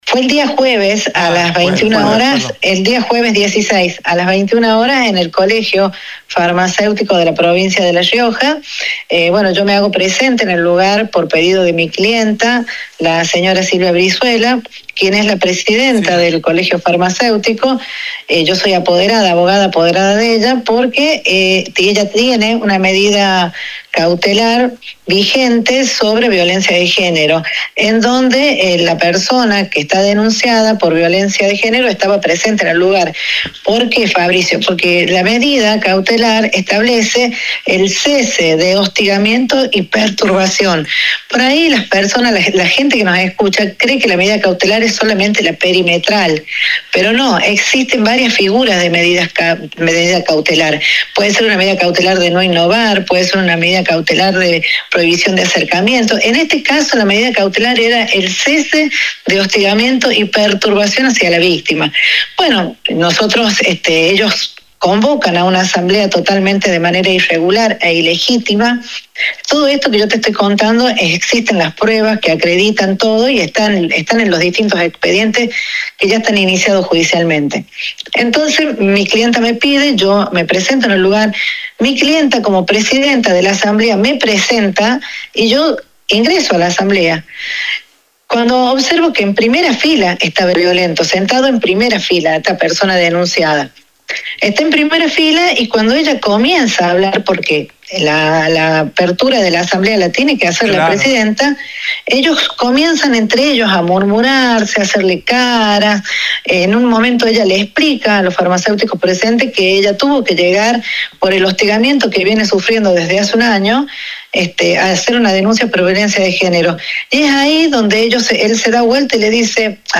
En dialogó con radio Libertad, aclaró que el procedimiento se inició por una denuncia de violencia de género y apuntó contra la Dra.